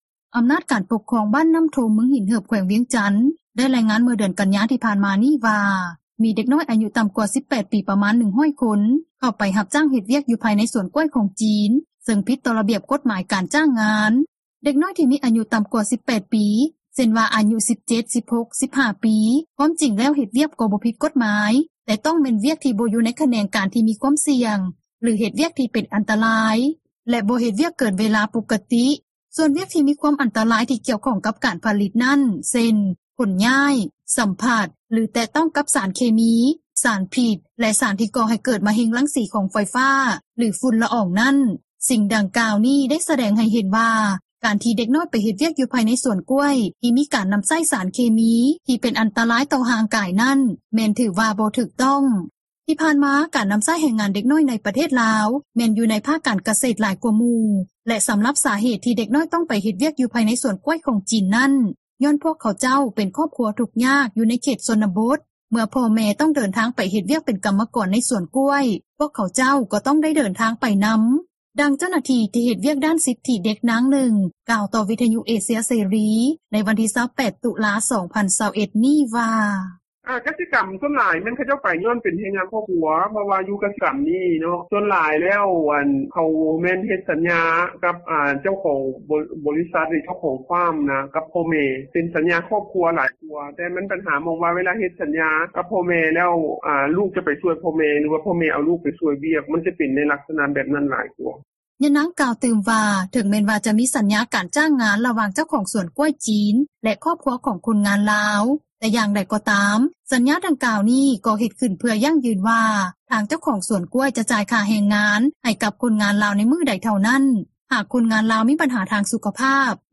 ທີ່ຜ່ານມາ ການນໍາໃຊ້ແຮງງານເດັກນ້ອຍ ໃນປະເທດລາວ ແມ່ນຢູ່ໃນພາກການ ກະເສດຫຼາຍກວ່າໝູ່ ແລະສໍາລັບຫາເຫດ ທີ່ເດັກນ້ອຍຕ້ອງເຮັດວຽກ ຢູ່ພາຍໃນສວນກ້ວຍຂອງຈີນນັ້ນ ຍ້ອນພວກເຂົາເຈົ້າເປັນຄອບຄົວທຸກຍາກ ຢູ່ໃນເຂດຊົນບົດ ເມື່ອພໍ່ແມ່ຕ້ອງເດີນທາງໄປເຮັດວຽກ ເປັນກັມມະກອນໃນສວນກ້ວຍ ພວກເຂົາເຈົ້າ ກໍຕ້ອງໄດ້ເດີນທາງໄປນໍາ, ດັ່ງເຈົ້າໜ້າທີ່ ທີ່ເຮັດວຽກດ້ານສິດທິເດັກ ນາງນຶ່ງ ກ່າວຕໍ່ວິທຍຸເອເຊັຽເສຣີ ໃນວັນທີ 28 ຕຸລາ 2021 ນີ້ວ່າ: